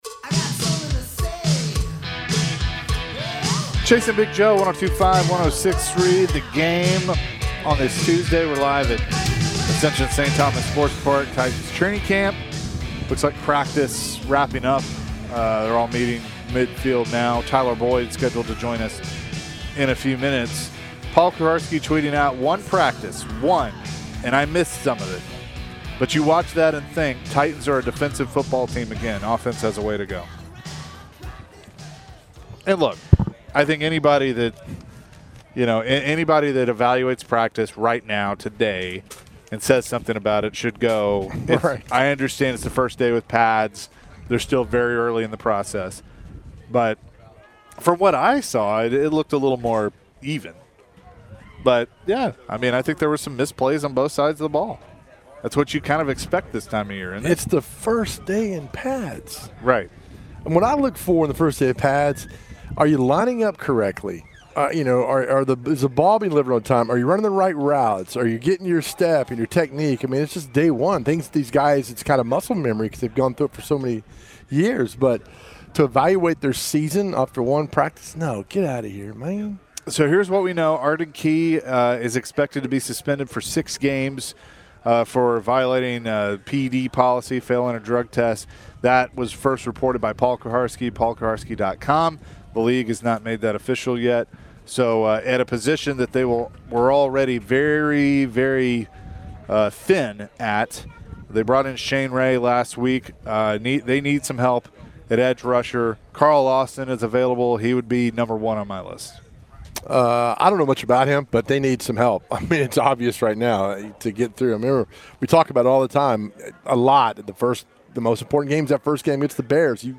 The latest addition to the Titans roster Tyler Boyd joined the show.